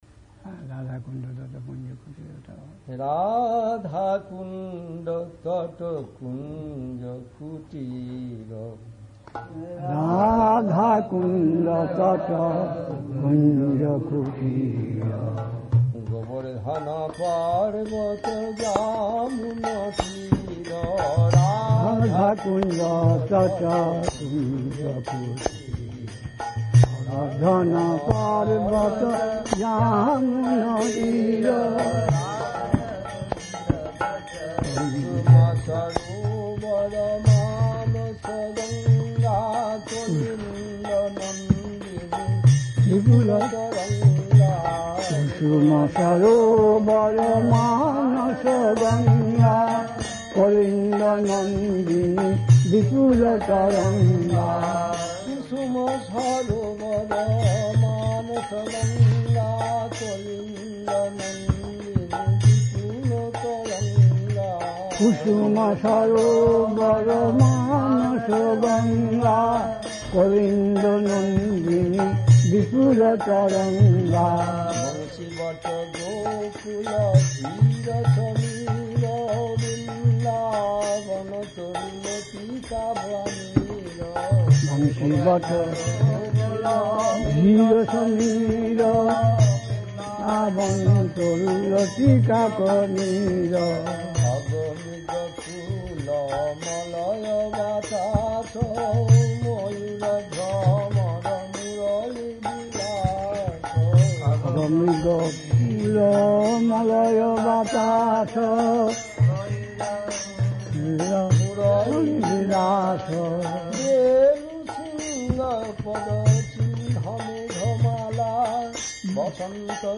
It's being posted as vintage classic recordings.
Place: SCSMath Nabadwip
Kirttan